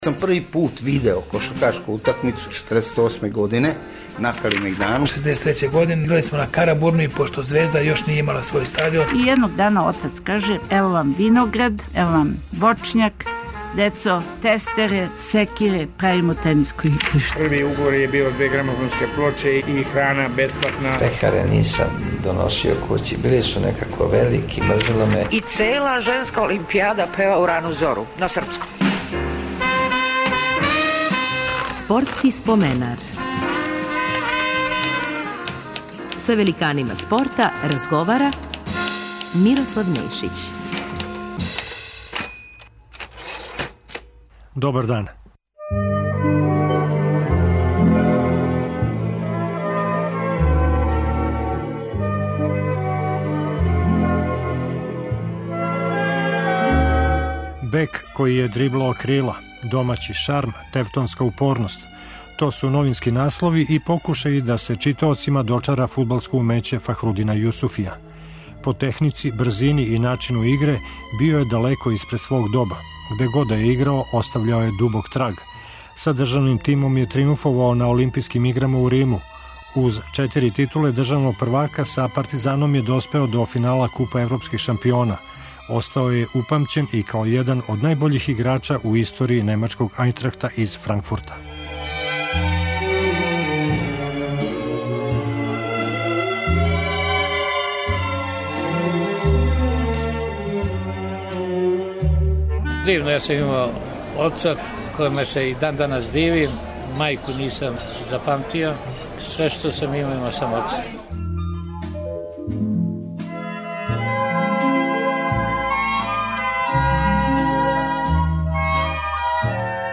Наш гост је фудбалер Фахрудин Јусуфи.